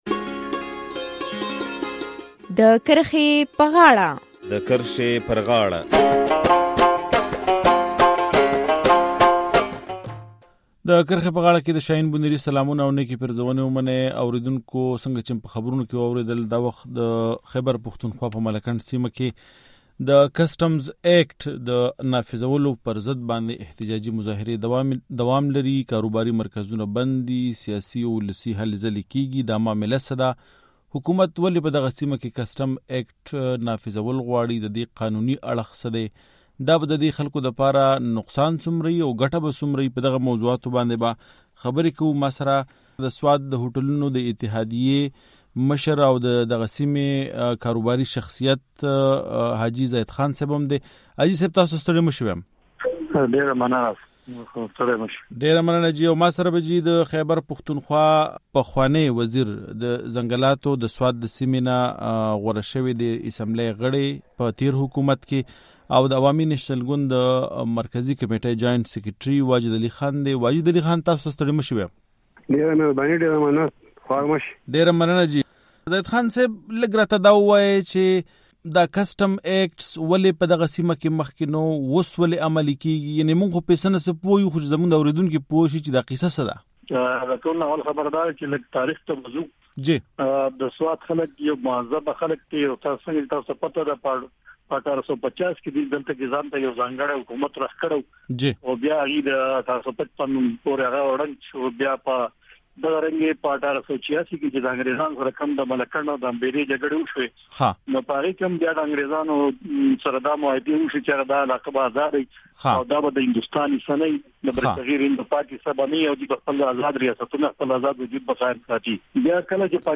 د کرښې پر غاړه کې په دغه موضوع بحث کوو.